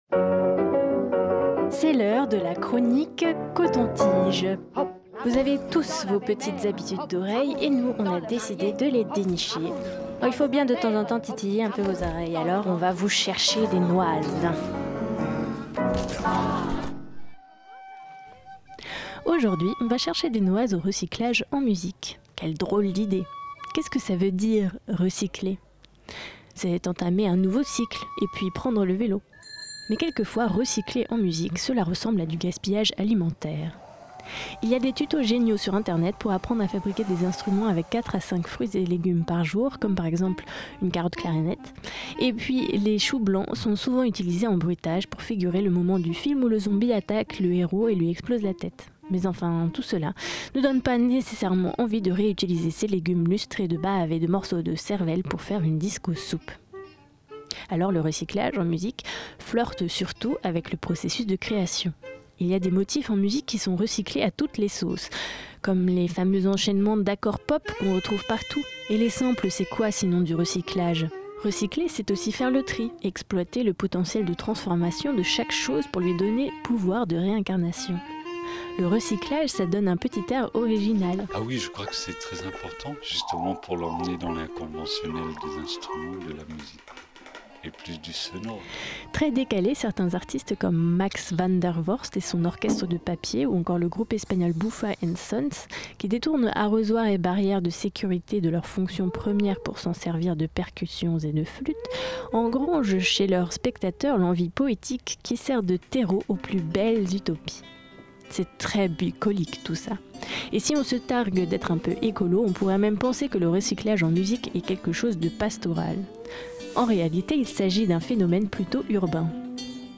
Chronique pêle-mêle où s'embrassent les épluchures et les godillots.
-Un sample de gobelet :